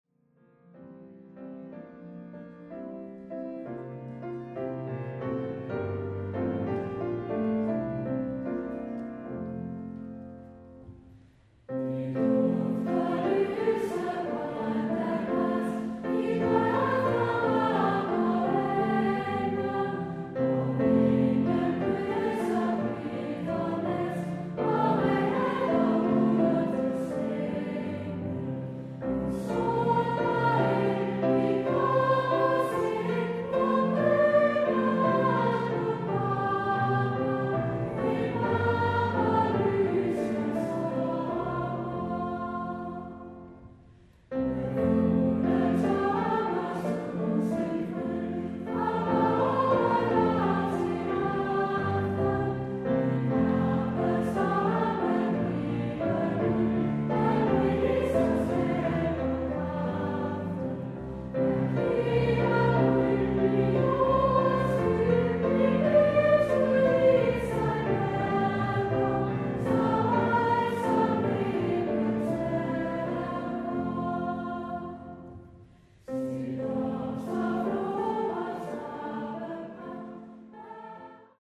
Vor Frue Kirkes Pigekor
(Fællessalme)